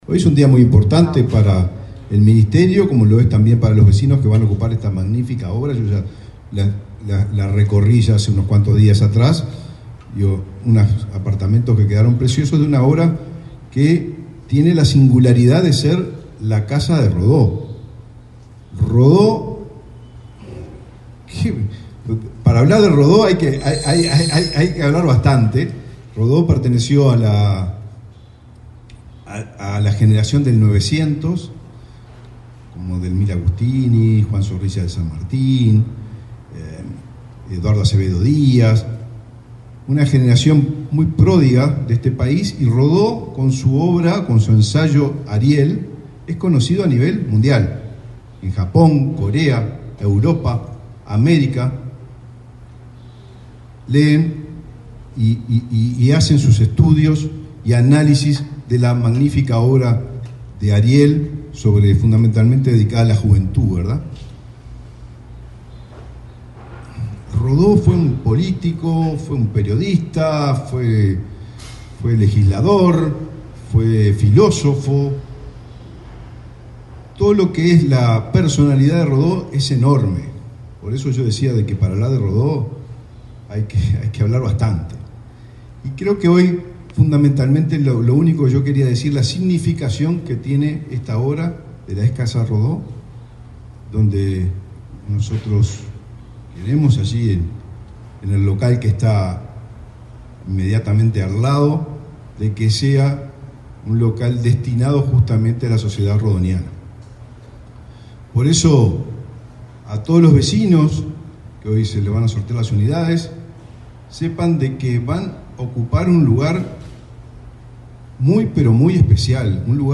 El ministro de Vivienda, Raúl Lozano, se expresó, durante el sorteo de unidades del edificio Casa Rodó, en Montevideo, en el marco del proyecto piloto